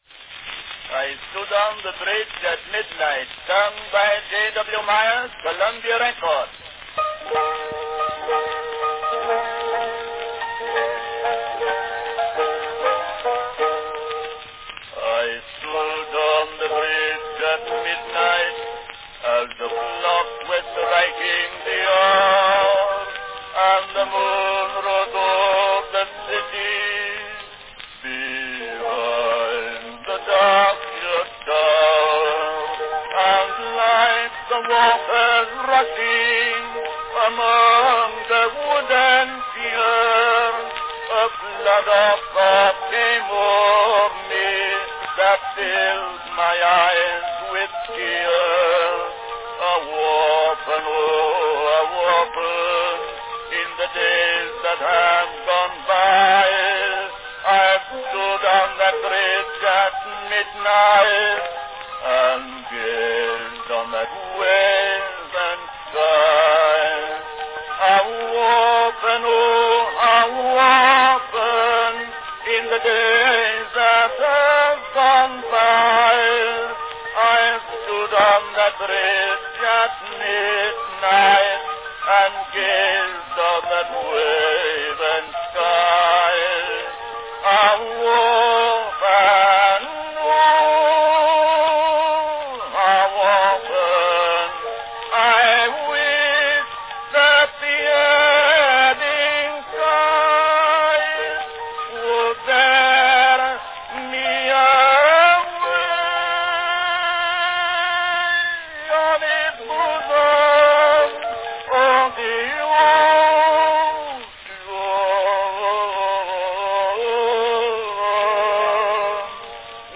Category Baritone solo
Typical of many two-minute wax cylinders, there was only enough recording time for just the first verse of Lindsay's two-verse condensation (see below) – leaving the work in a decidedly melancholy tone.